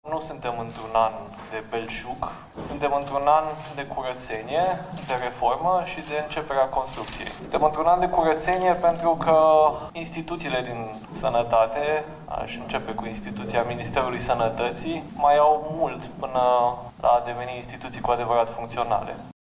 Nu este un an de belșug, este un an „de curățenie” în sistemul sanitar – spune ministrul Sănătății în comisiile de specialitate din Parlament, unde se discută Bugetul.
Vlad Voiculescu, în urmă cu puțin timp, în fața colegilor parlamentari: